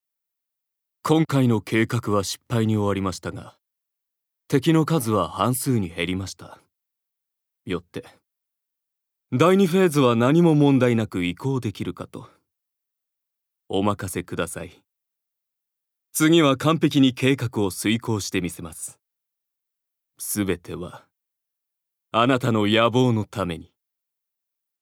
ボイスサンプル
セリフ４